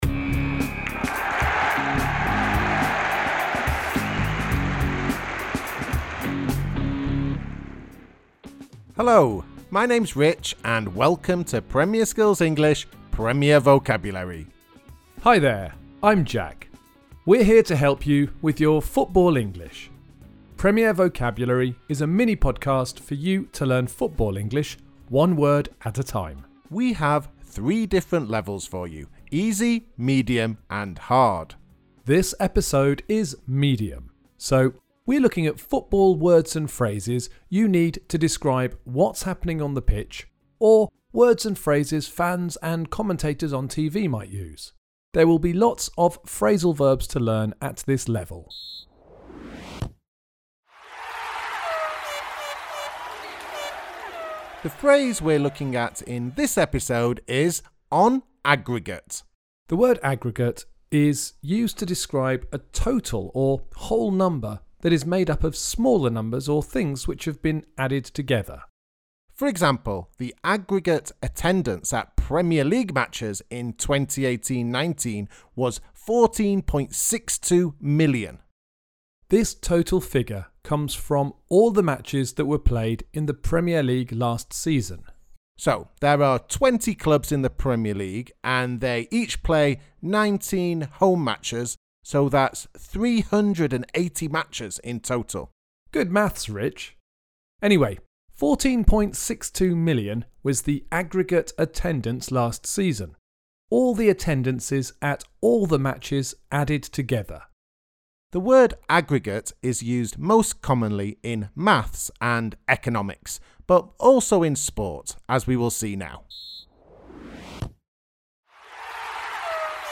Premier Vocabulary is a mini-podcast for you to learn football English one word at a time.